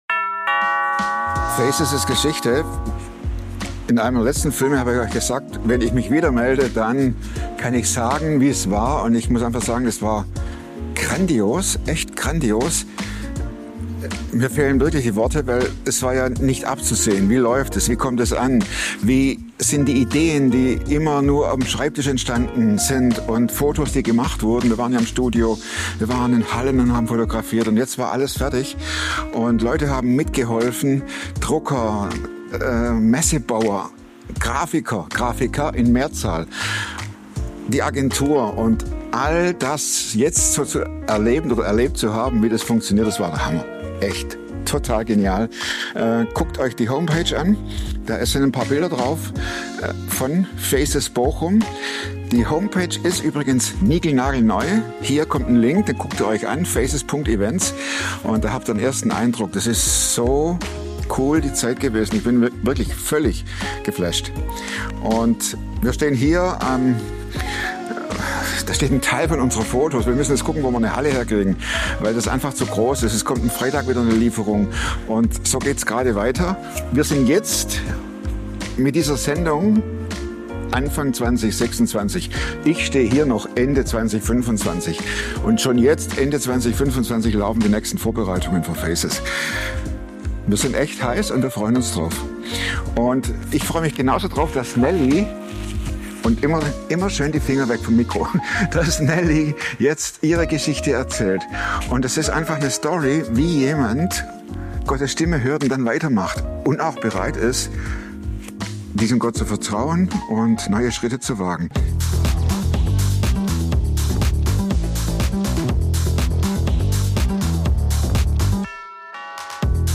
Ein Gespräch über Menschlichkeit, Glauben und Hoffnung an einem Ort, den viele lieber ausblenden.